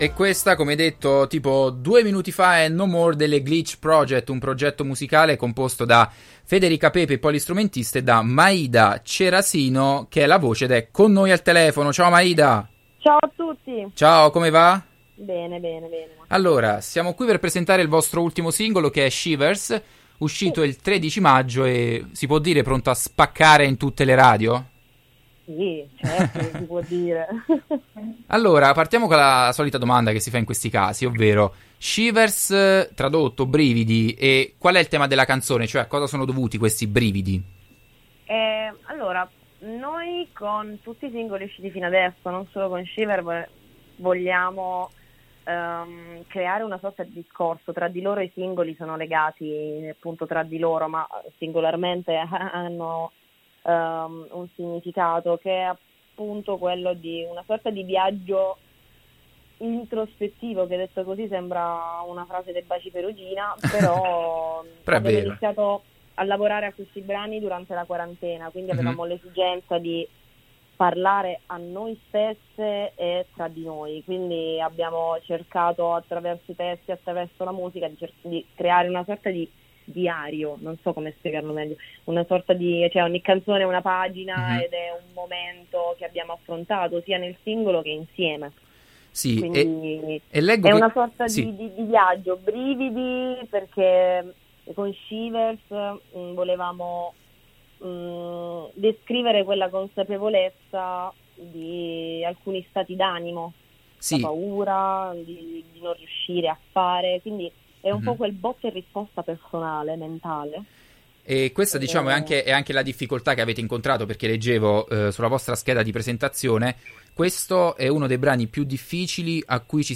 Intervista-Glitch-Project.mp3